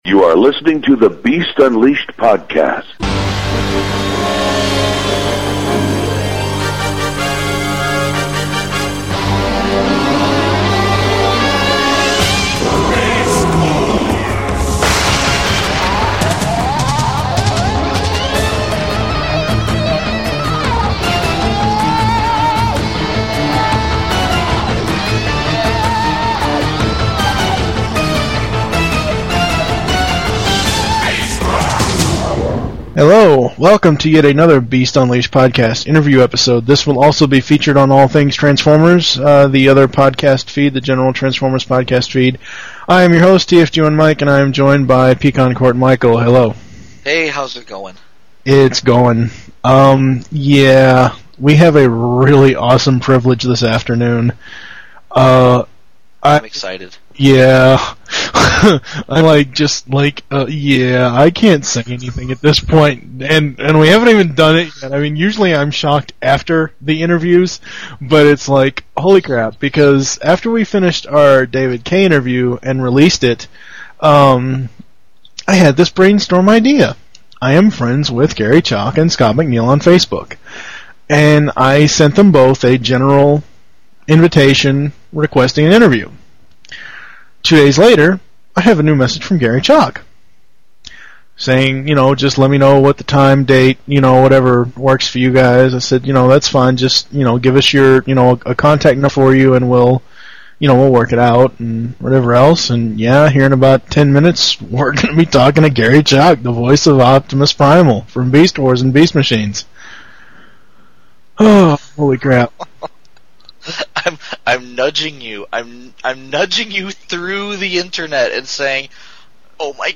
Interviews – Garry Chalk